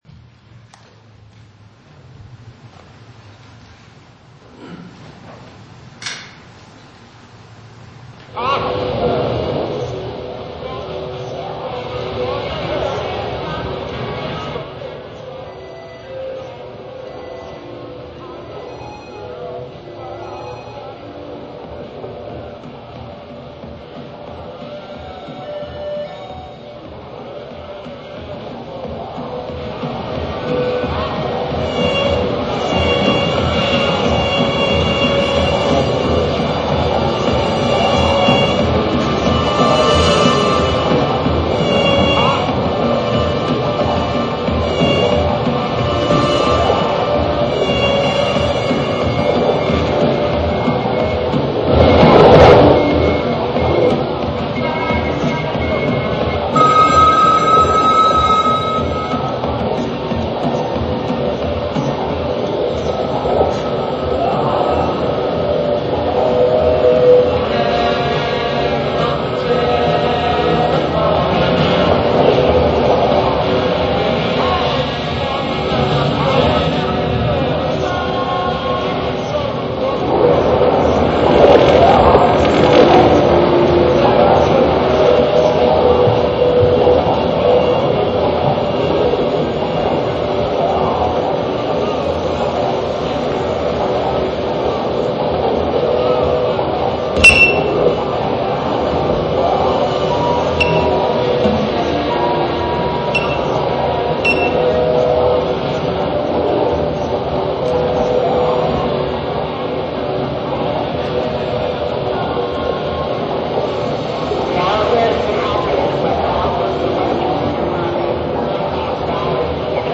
FM transmitters, radios, scanners, and samplers